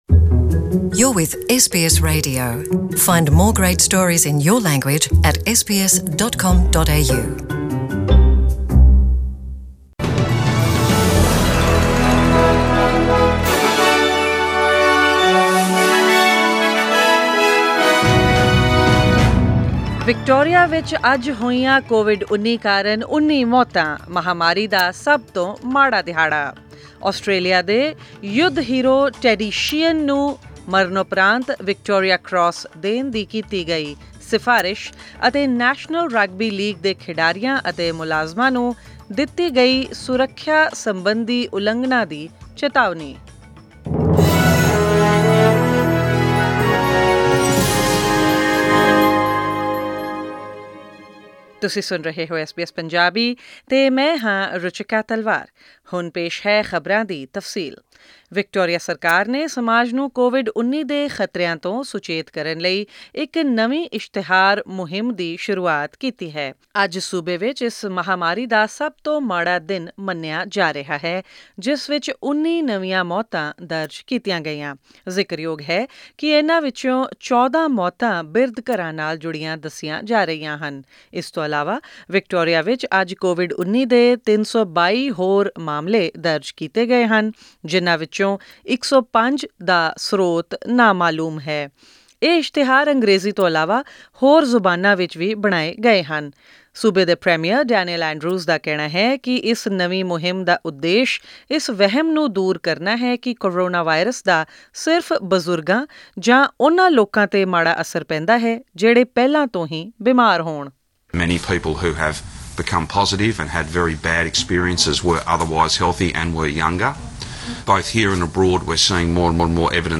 Australian News in Punjabi: 10 August 2020